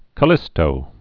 (kə-lĭstō)